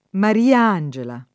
vai all'elenco alfabetico delle voci ingrandisci il carattere 100% rimpicciolisci il carattere stampa invia tramite posta elettronica codividi su Facebook Mariangela [ mari- # n J ela ] (raro Maria Angela [ mar & a # n J ela ]) pers. f.